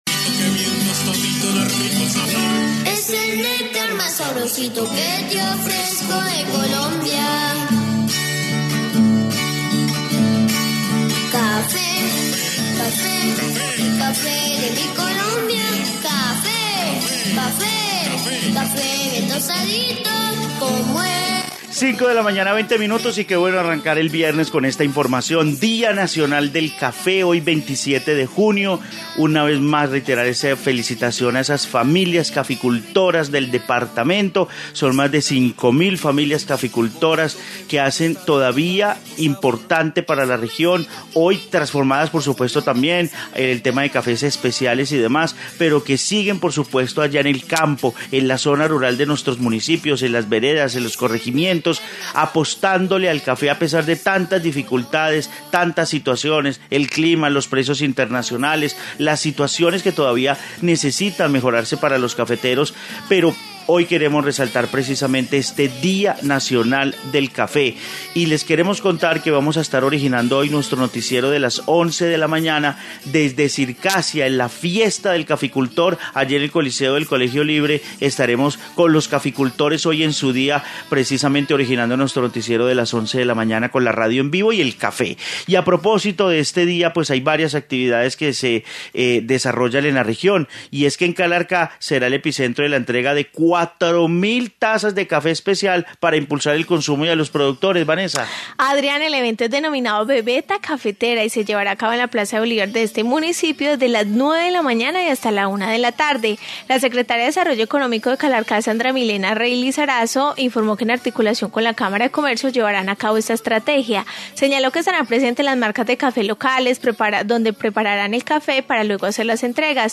Informe sobre el día nacional del café